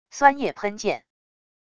酸液喷溅wav音频